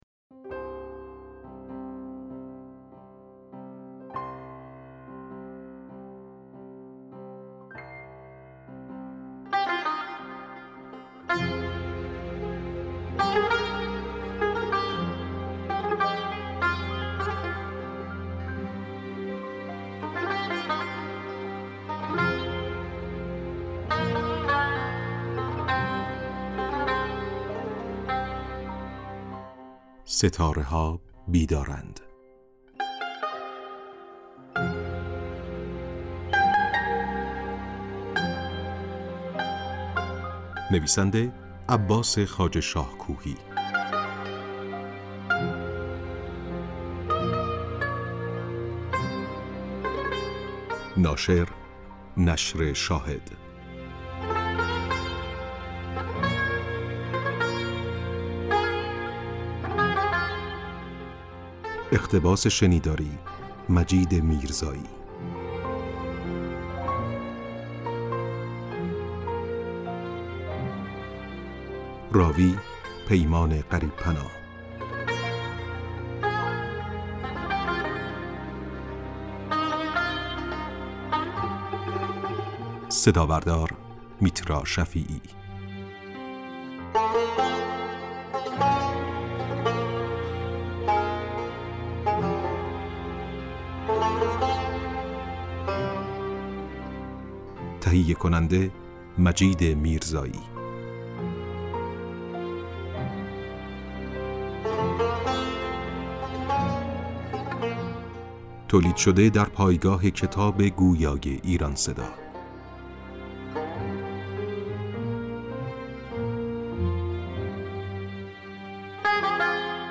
نسخه صوتی کتاب «ستاره‌ها بیدارند» در نوید شاهد منتشر شد
در ادامه نسخه صوتی کتاب «ستاره ها بیدارند» را بشنوید و دریافت کنید.